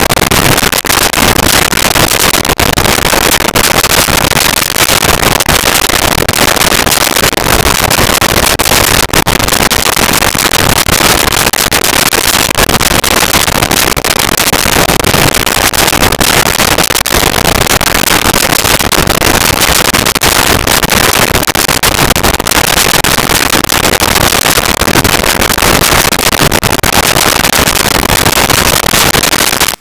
Stenographer Typing
Stenographer Typing.wav